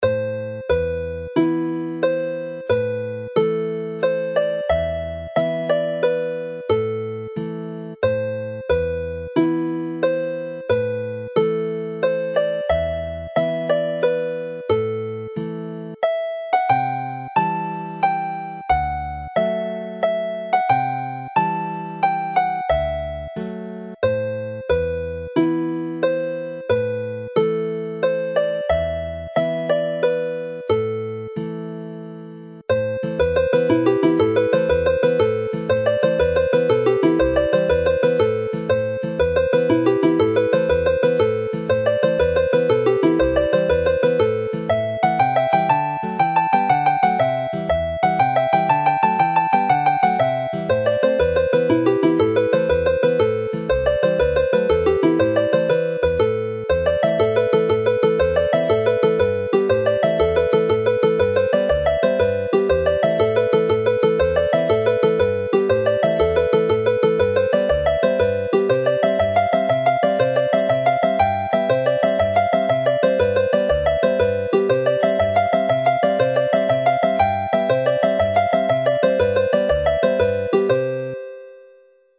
Y Gog Lwydlas is haunting minor key song in the Dorian mode.
The set concludes with the rip-roaring slip jig Neidod Twm Bach (little Tom's prank) from the excellent collection of 100 dance tunes in the second book of tunes collected from the National Library archives by Robin Huw Bowen and published by the Welsh Folk Dance Society, Cadw Twmpath.